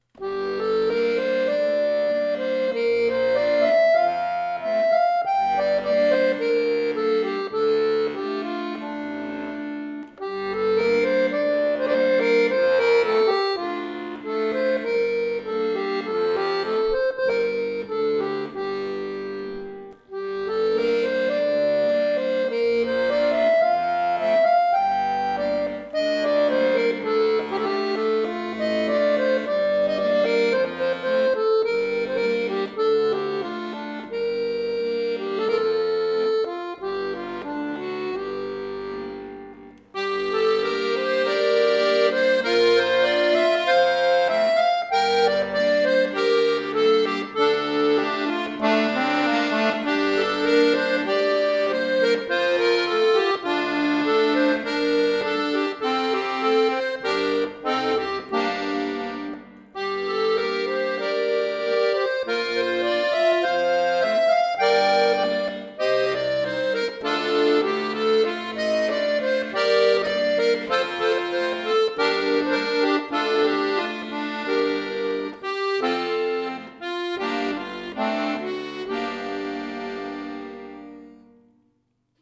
Folk, Irisch, Klassisch